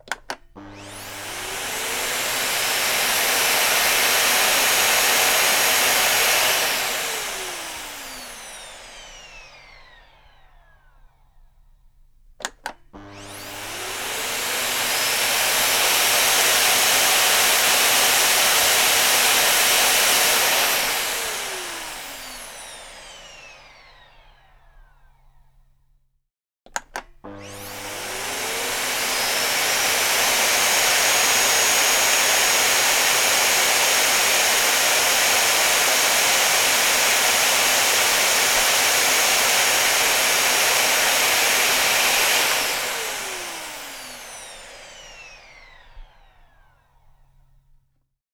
KIuqXbr45j4-MACHAppl-Aspirateur-allume-et-eteint-ID-0721-LS.mp3